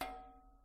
sfx_walk_metal_4.mp3